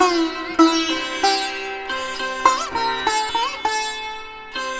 100-SITAR6-L.wav